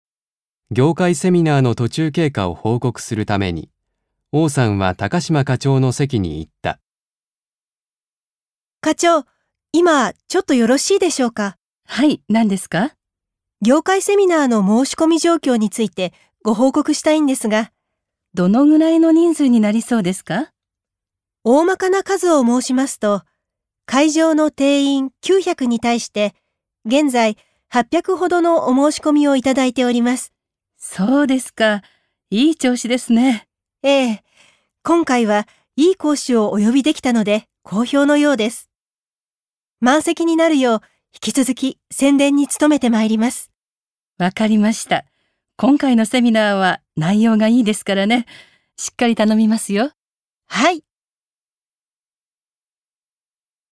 1. 会話（業務ぎょうむが今どのような状況じょうきょう上司じょうしに報告する）